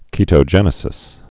(kētō-jĕnĭ-sĭs)